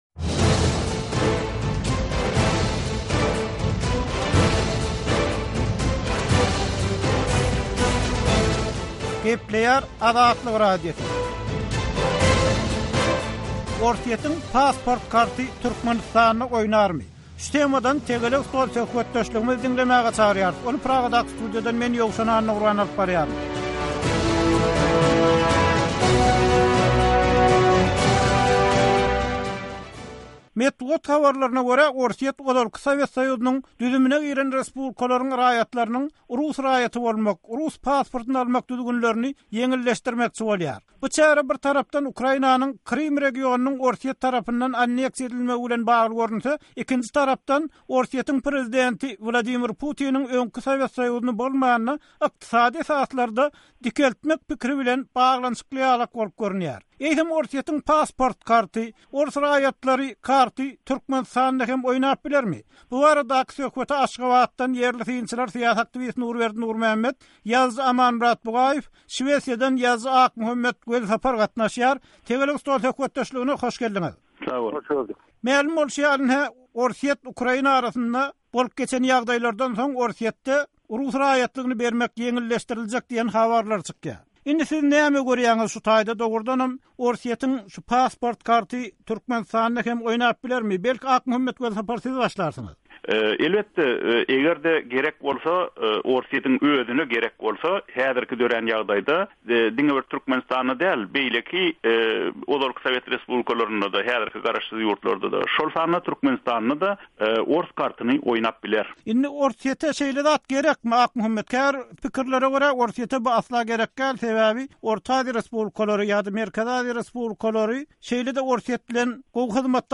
Tegelek stol: Türkmenistanyň "rus howpy"